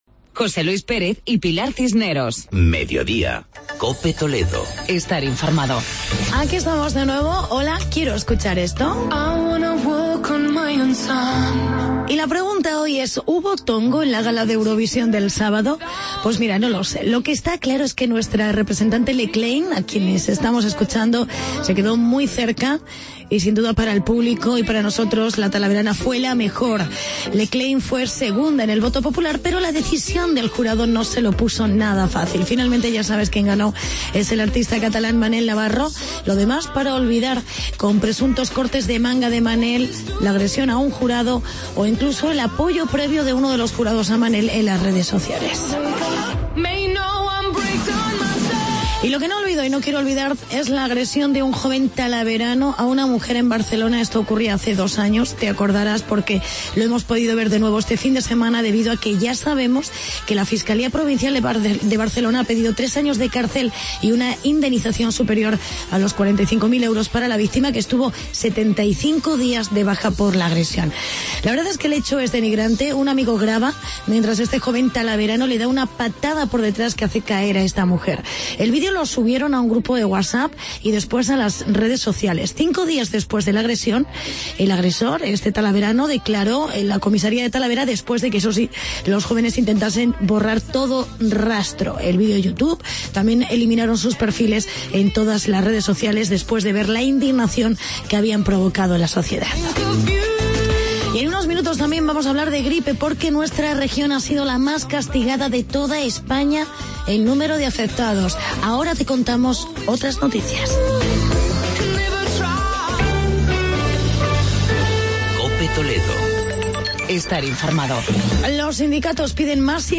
Actualidad y reportaje sobre el decálogo de recomendaciones sobre la gripe en niños.